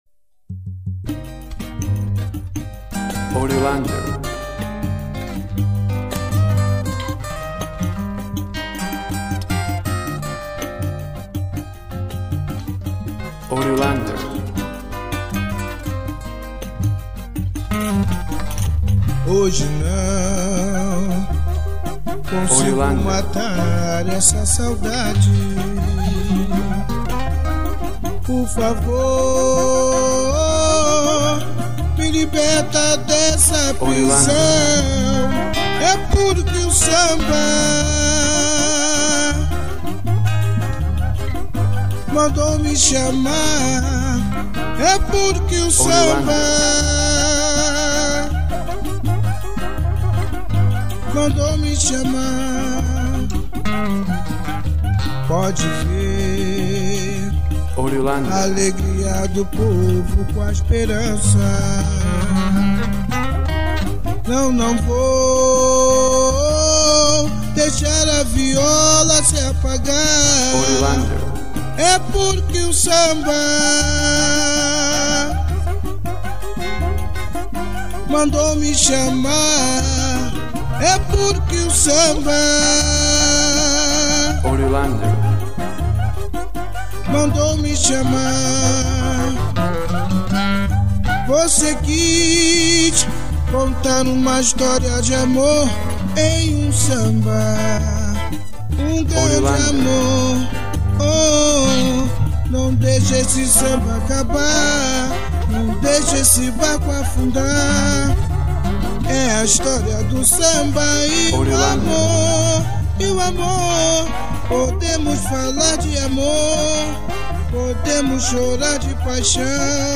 Sound root samba from Rio de Janeiro.
WAV Sample Rate 16-Bit Stereo, 44.1 kHz
Tempo (BPM) 70